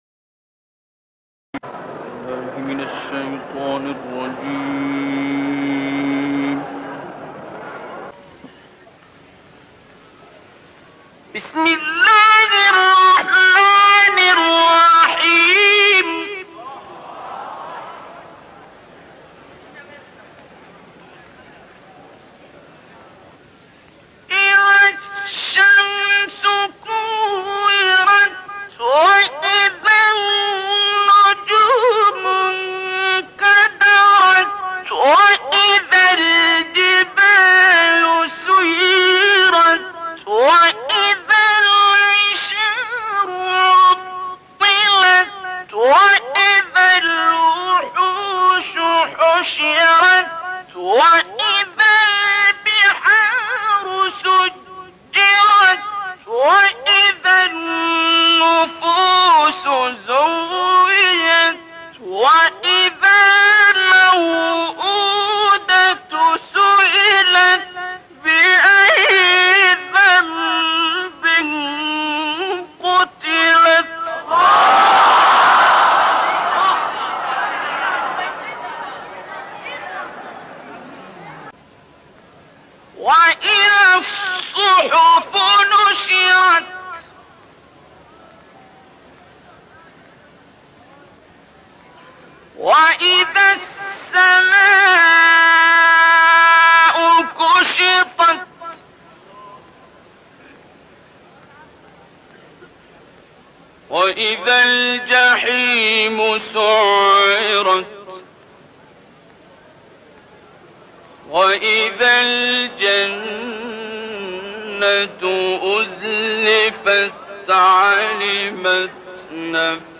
با خواندن این سوره از رسوایی در روز قیامت در امانید +متن و ترجمه+ تلاوت استاد عبدالباسط